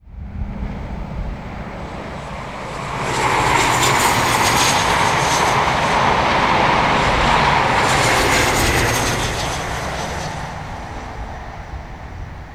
1. 01. Passing Train ZG